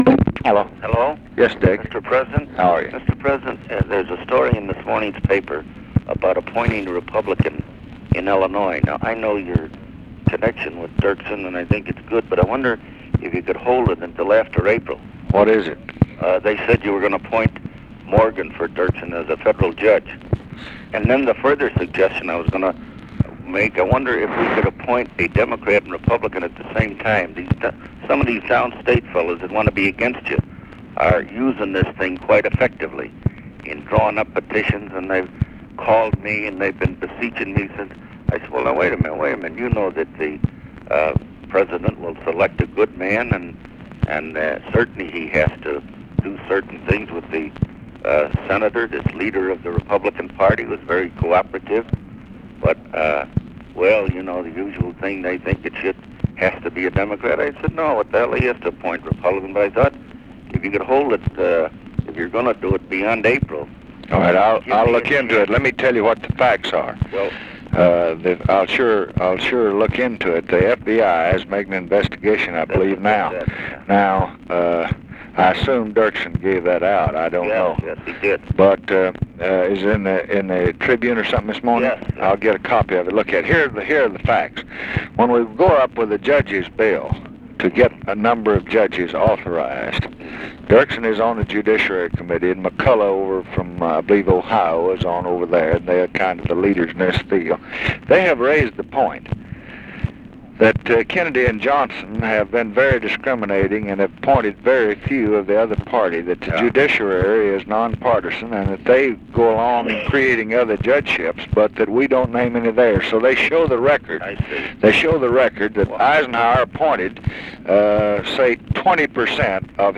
Conversation with RICHARD DALEY, February 14, 1967
Secret White House Tapes